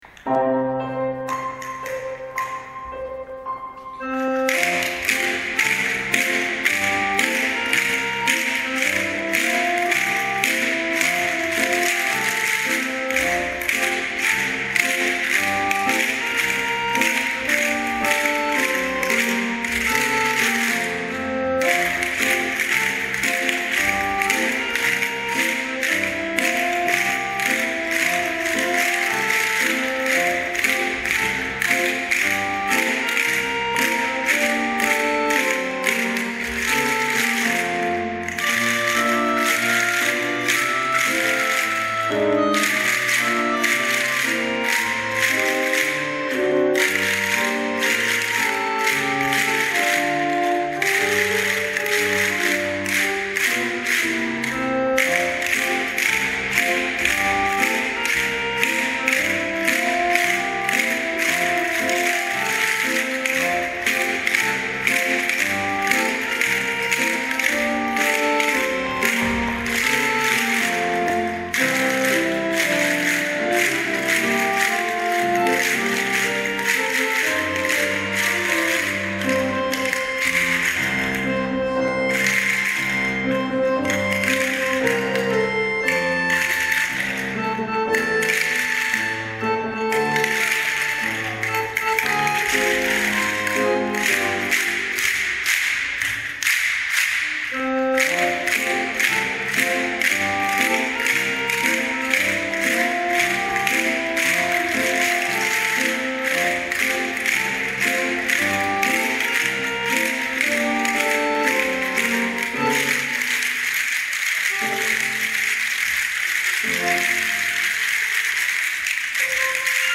みんなで息を合わせてカスタネットで演奏しました。初めてのリズム奏でしたが、友達のリズムを感じながら学習をすることができました。